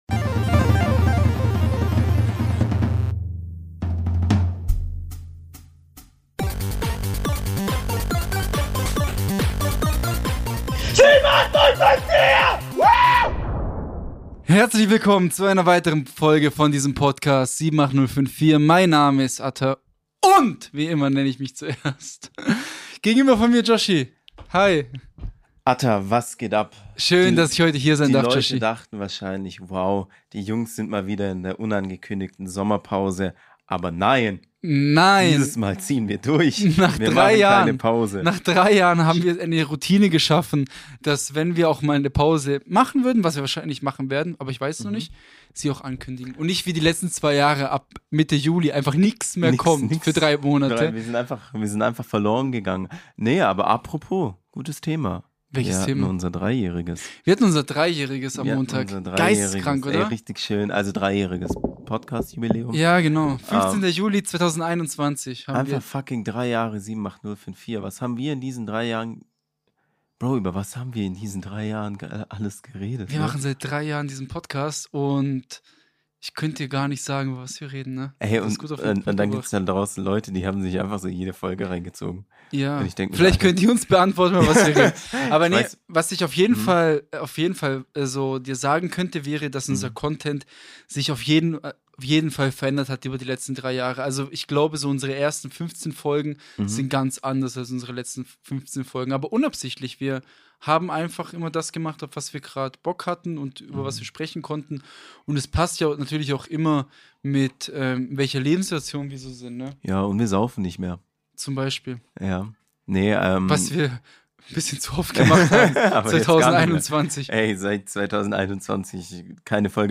Das komplette Interview am Ende der Folge!